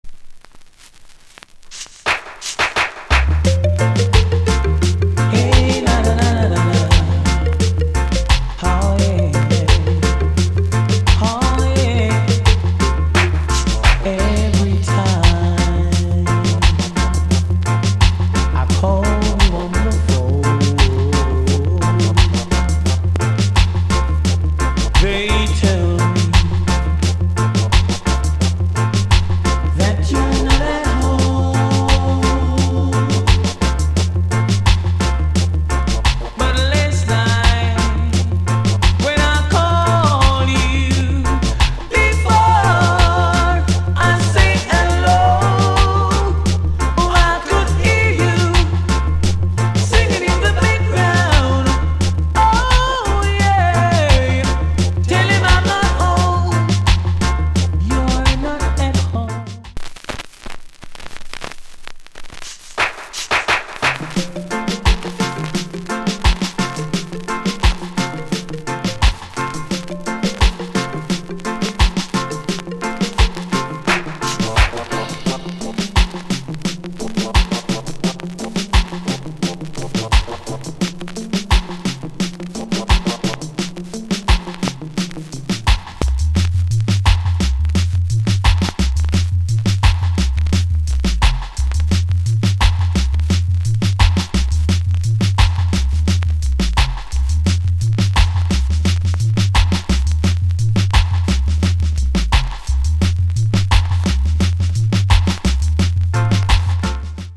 Cover Nice Vocal